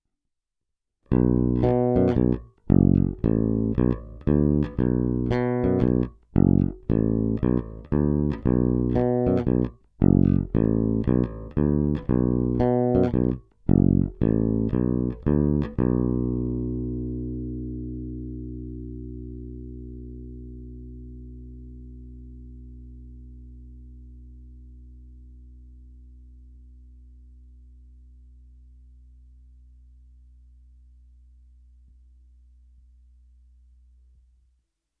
Není-li uvedeno jinak, nahrávky jsou provedeny rovnou do zvukové karty, bez stažené tónové clony a bez použití korekcí.
Hráno nad použitým snímačem, v případě obou hráno mezi nimi.
Snímač u kobylky